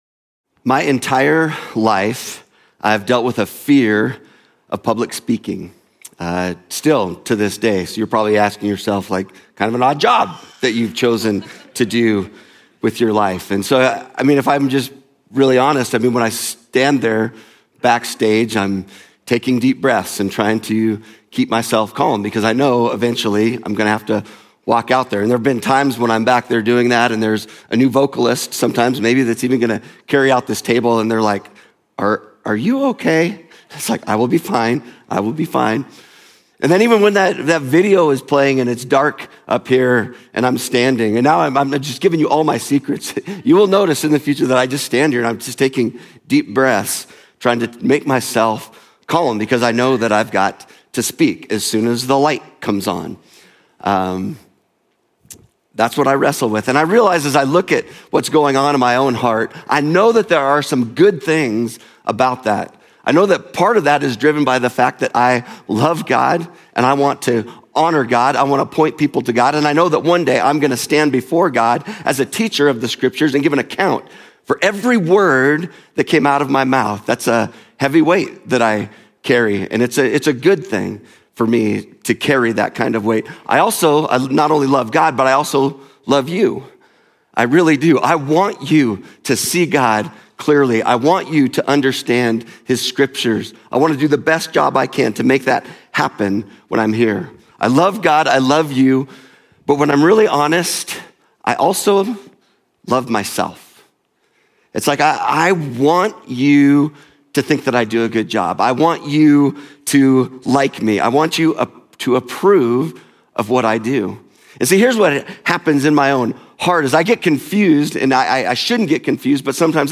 Journey Church Bozeman Sermons